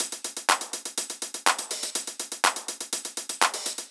ENE Beat - Perc Mix 2.wav